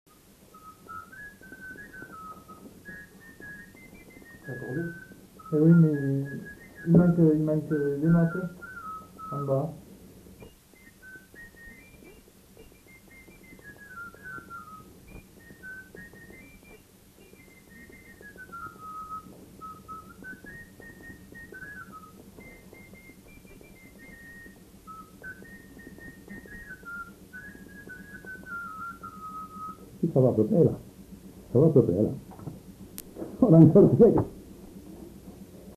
Aire culturelle : Gabardan
Lieu : Estigarde
Genre : morceau instrumental
Instrument de musique : flûte de Pan
Danse : rondeau